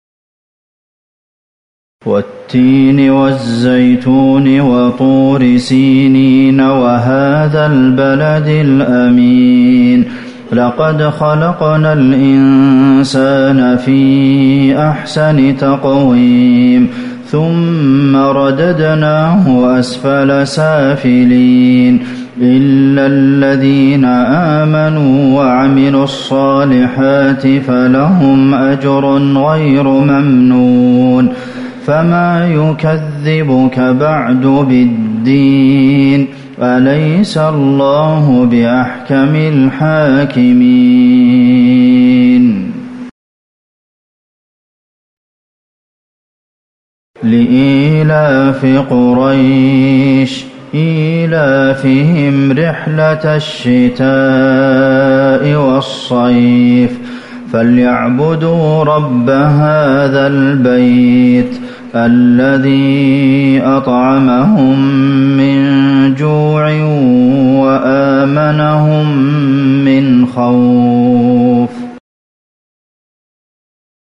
مغرب 3-1-1441هـ سورتي التين وقريش | Maghrib prayer Surah At-Tin and Quraysh > 1441 🕌 > الفروض - تلاوات الحرمين